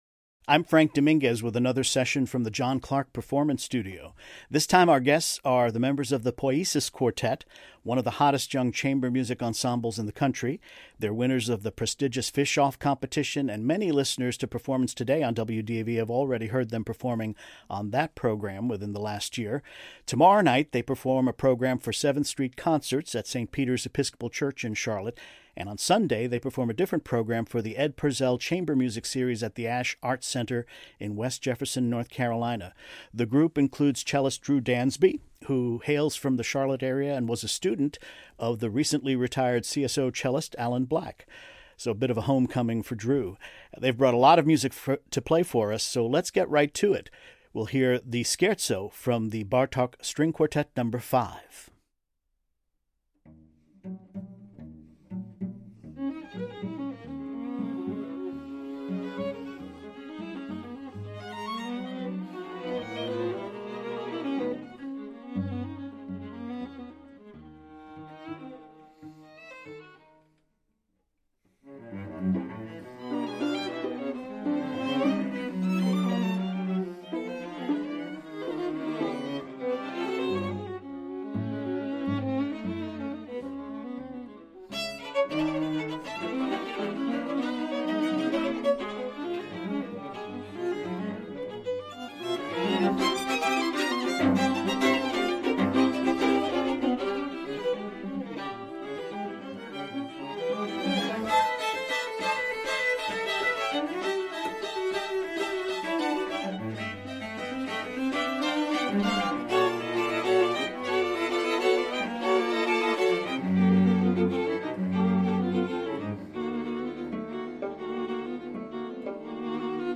Classical Chamber